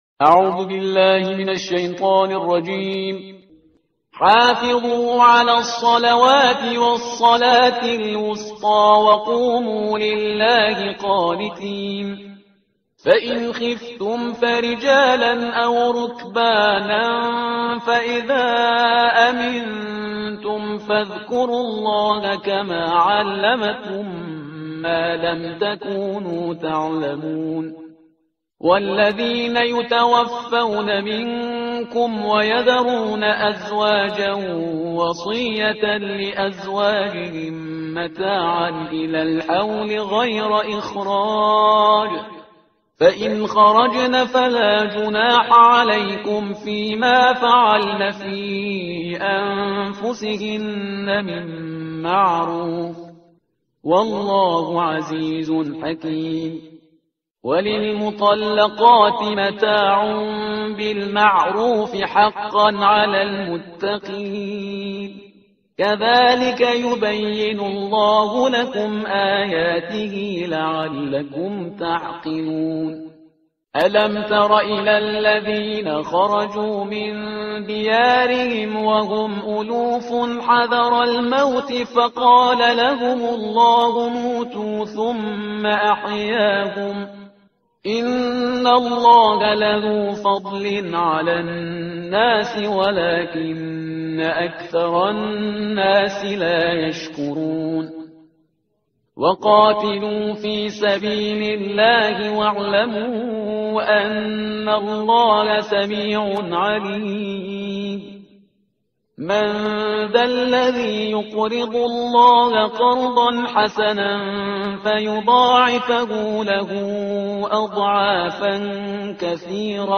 ترتیل صفحه 39 قرآن با صدای شهریار پرهیزگار
Parhizgar-Shahriar-Juz-02-Page-039.mp3